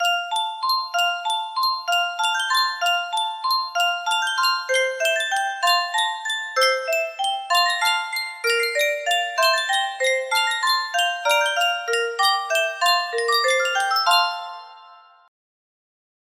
Yunsheng Music Box - Handel Music for the Royal Fireworks 4673 music box melody
Full range 60